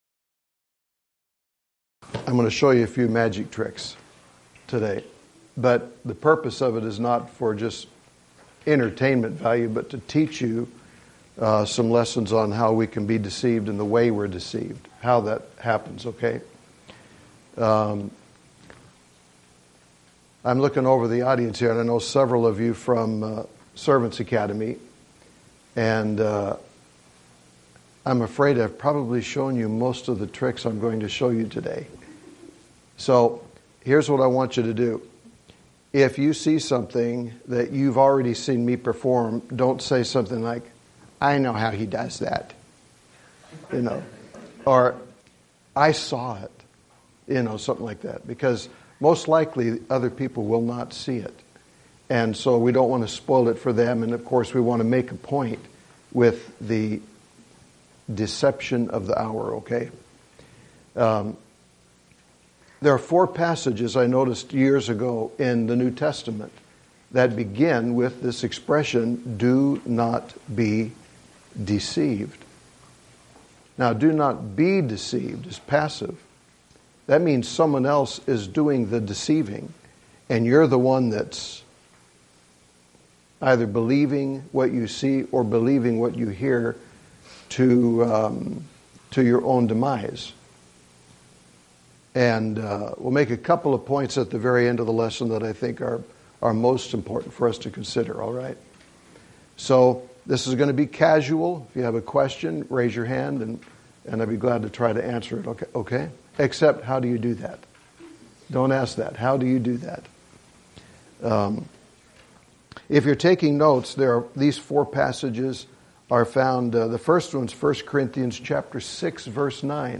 Event: 2019 Focal Point Theme/Title: Preacher's Workshop
Youth Sessions